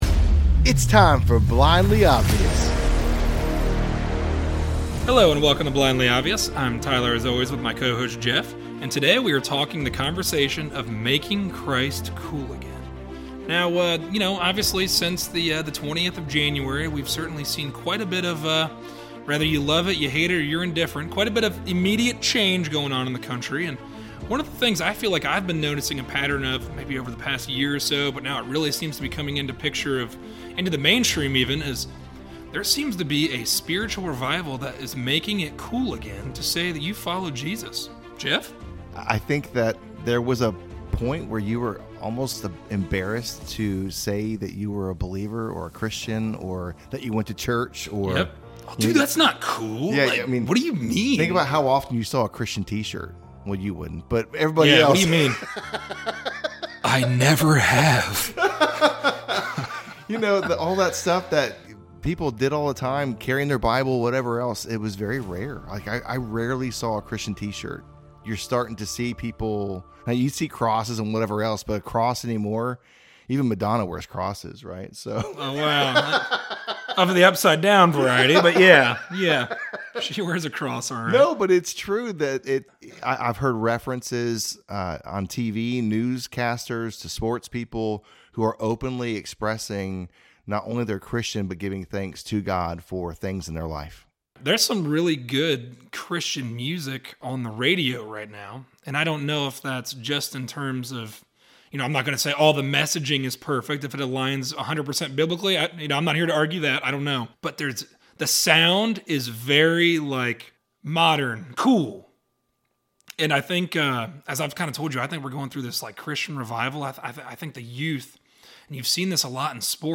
A conversation about the many fads that come and go. Once again, it is popular to be a person of FAITH.